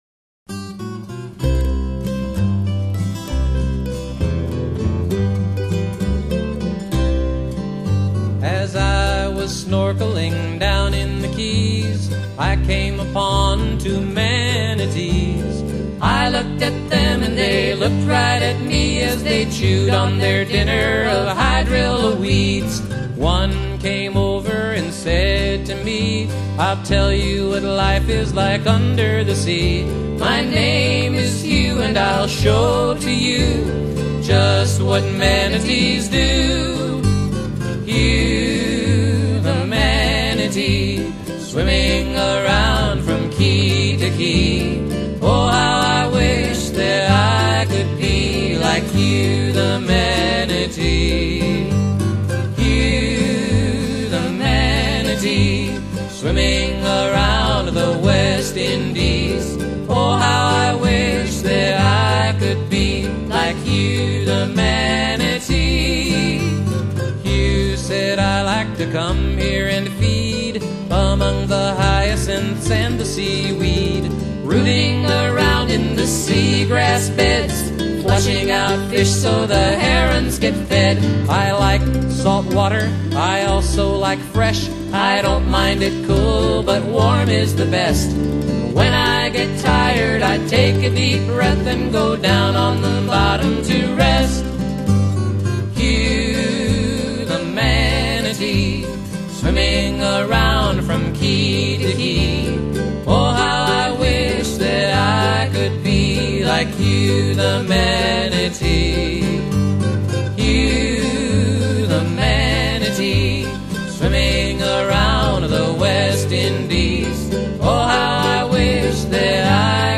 Earth songs for children of all ages